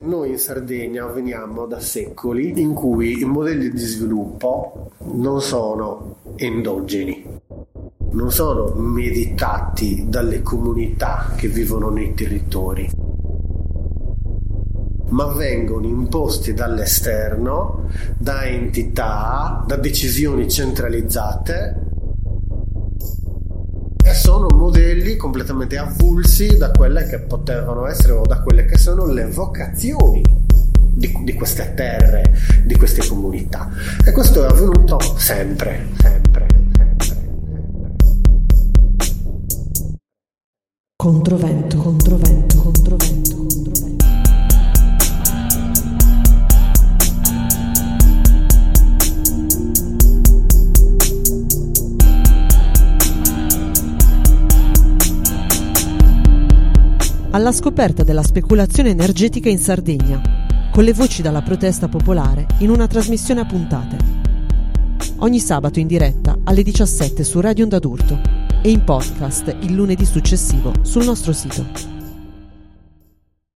Le testimonianze sono state registrate durante un viaggio alla scoperta della speculazione energetica in Sardegna tra ottobre e novembre 2024, raccolte da Radio Onda d’Urto, Radio Black Out e dal progetto di inchiesta Confluenza ospitato su Infoaut.